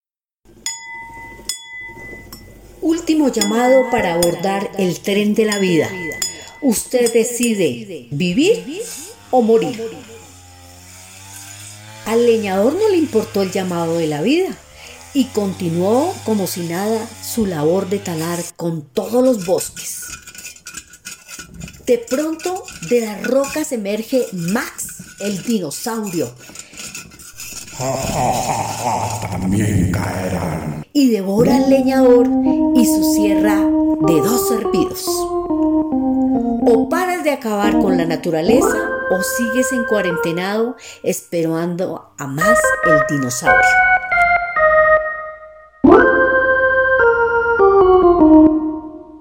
Podcast desarrollado en el taller de radio, que se ejecutó en el primer semestre del año 2020, bajo el marco del premio Daniel Samper Ortega, otorgado a la Biblioteca Pública Carlos E. Restrepo en el 2018. Contó con la participación intergeneracional de usuarios de la mencionada biblioteca.
Producción radial, Medio ambiente